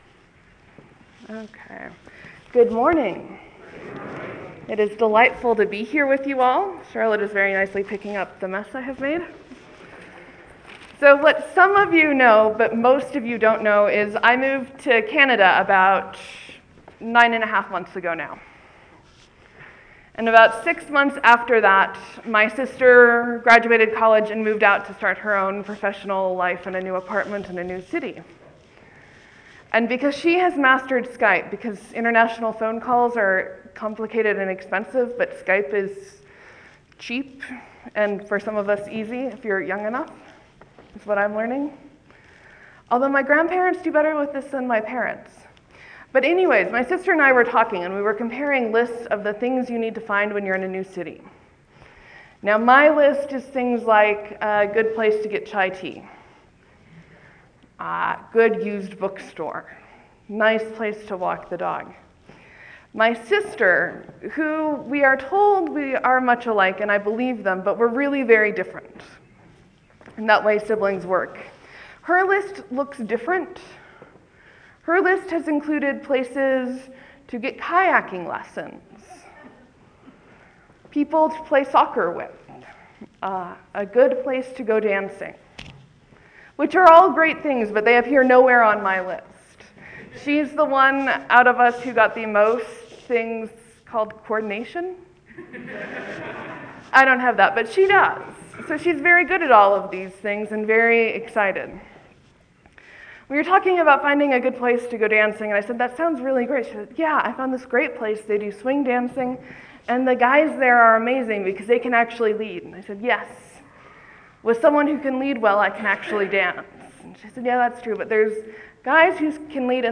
I preached on the Trinity, the doctrine of the day.